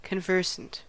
Ääntäminen
IPA: /ɛk.spe.ʁi.mɑ̃.te/